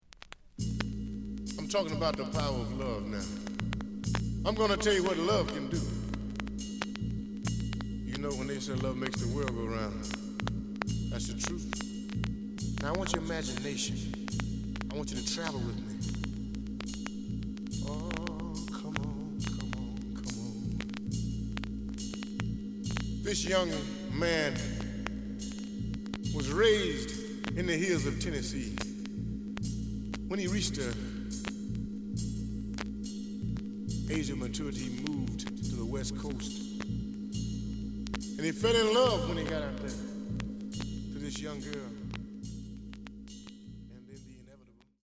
The audio sample is recorded from the actual item.
●Genre: Funk, 60's Funk
Some click noise on both sides due to scratches.